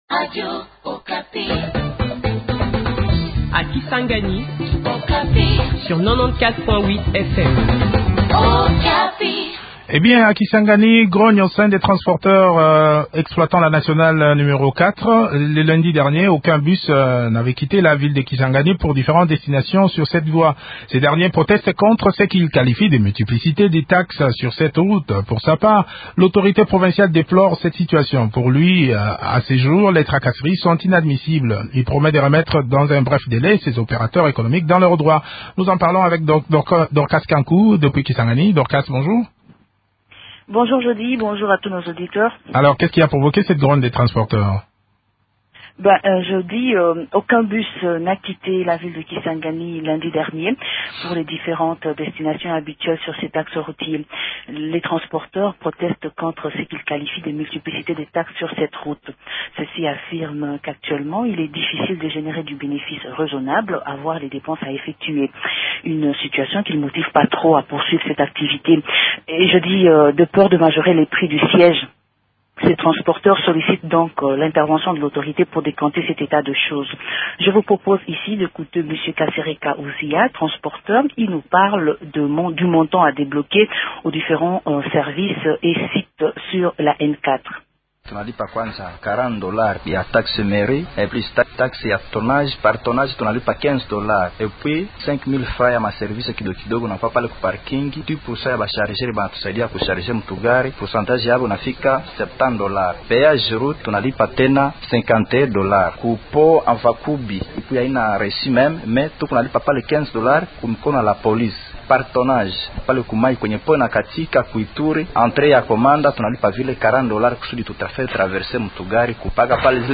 Joseph Bangyaka, vice gouverneur de la province orientale.